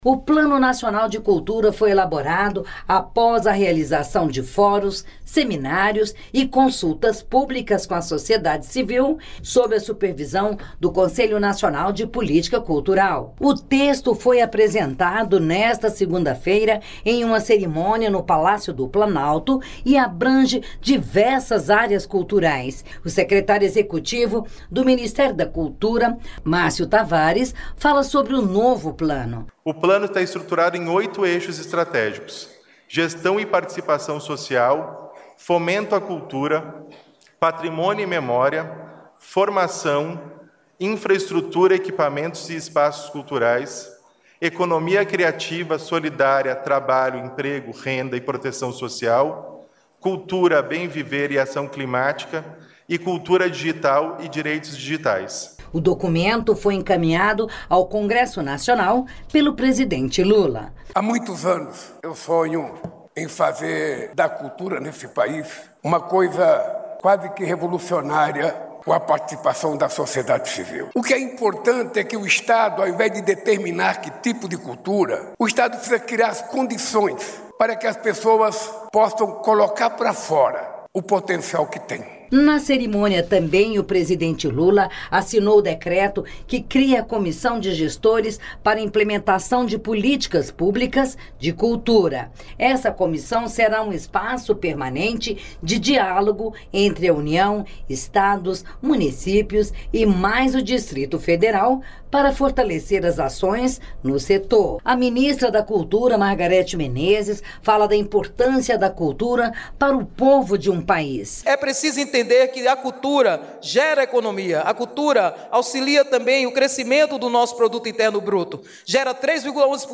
Nesta semana a Voz do Brasil apresenta uma série especial com três reportagens sobre a educação básica no Brasil.